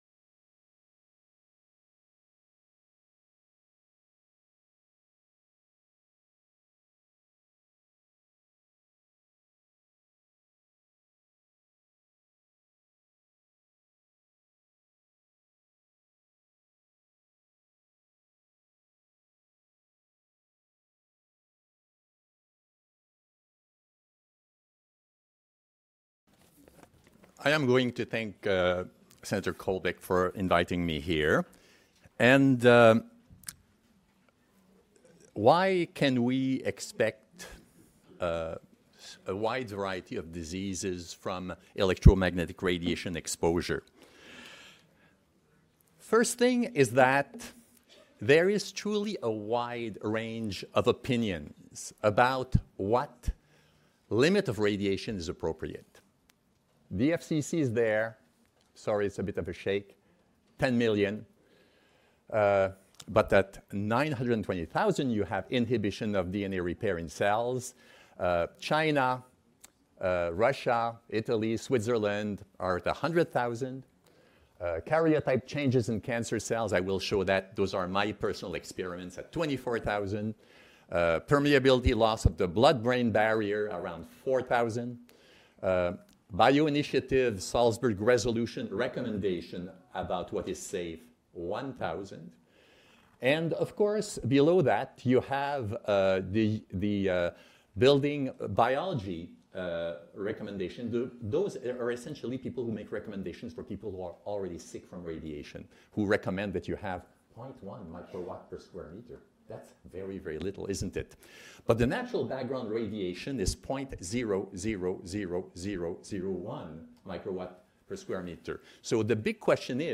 This lecture is from December 4th, 2018 5G Conference in Lansing,